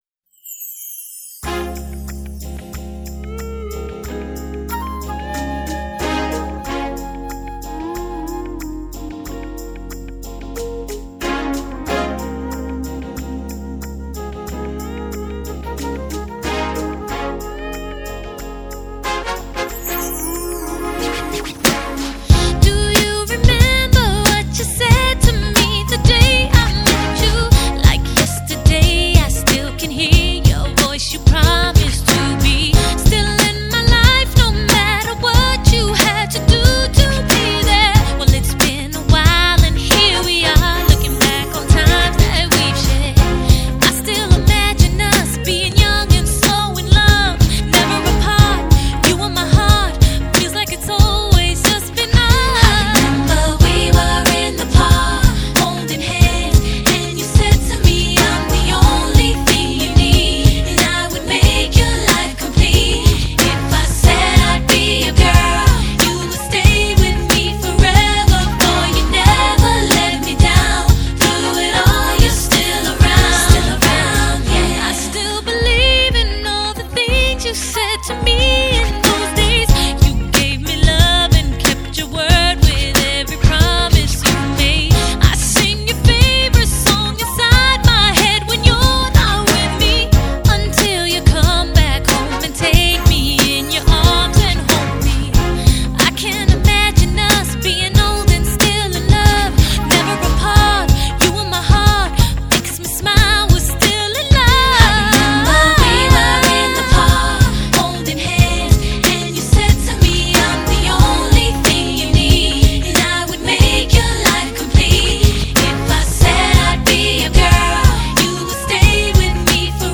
Pop, R&B